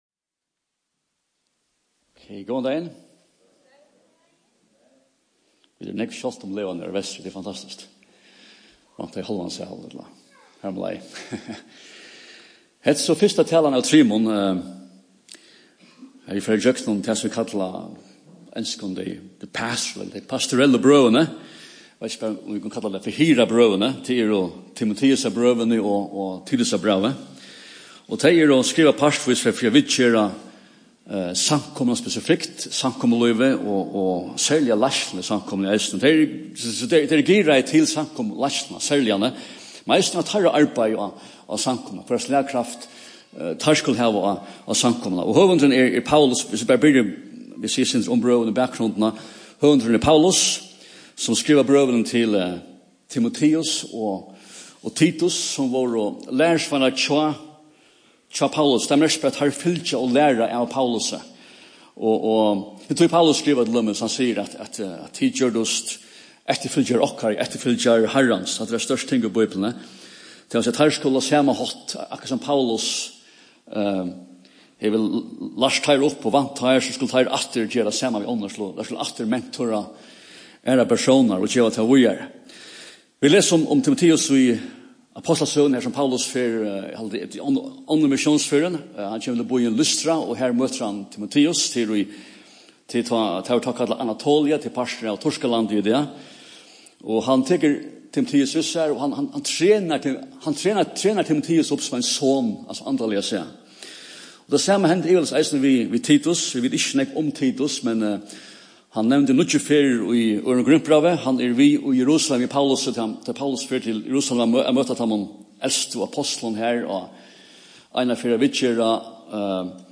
Fyrsta talan í røðini um Timoteusarbrøvini og Titusbrævið.